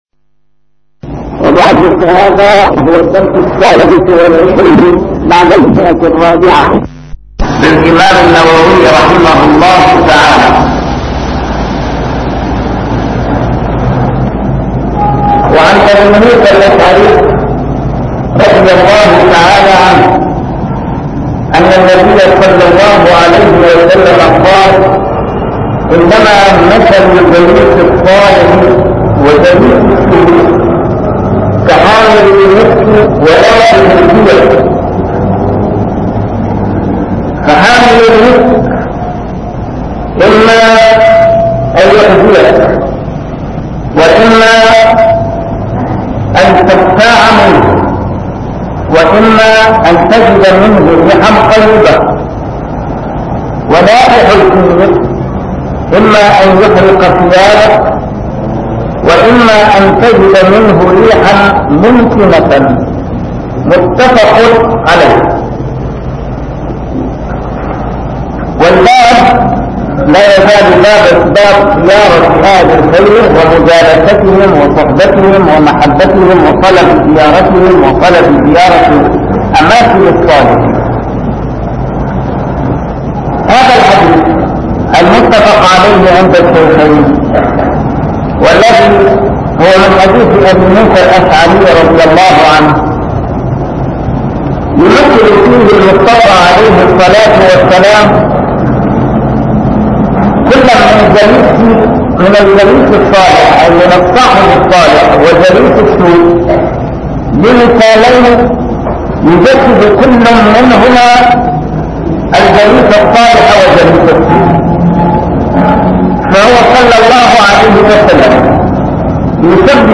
A MARTYR SCHOLAR: IMAM MUHAMMAD SAEED RAMADAN AL-BOUTI - الدروس العلمية - شرح كتاب رياض الصالحين - 426- شرح رياض الصالحين: زيارة أهل الخير